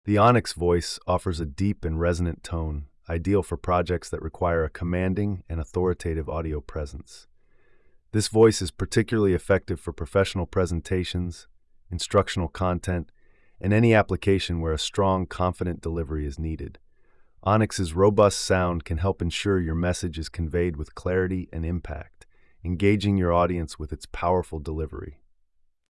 The “Onyx” voice offers a deep and resonant tone, ideal for projects that require a commanding and authoritative audio presence.
Onyx’s robust sound can help ensure your message is conveyed with clarity and impact, engaging your audience with its powerful delivery.